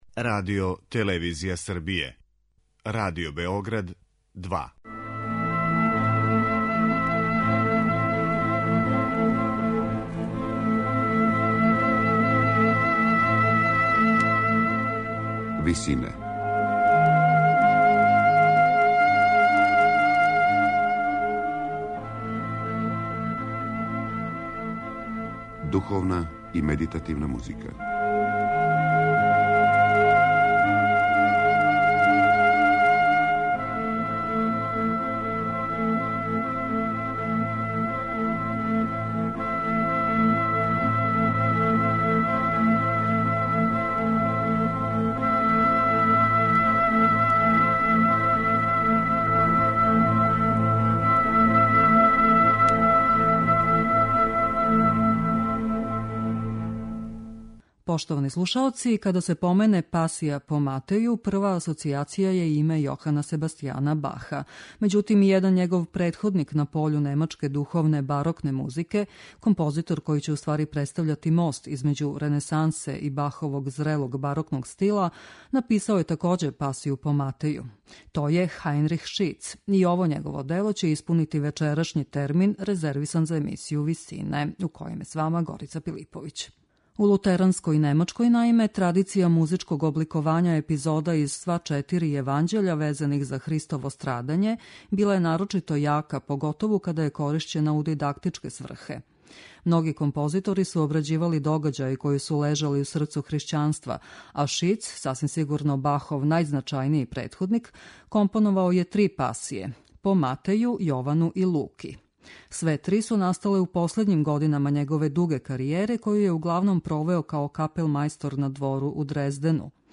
Међутим, и његов претходник на пољу немачке барокне музике Хајнрих Шиц написао је дело под истим насловом, које ће испунити вечерашњу емисију.
медитативне и духовне композиције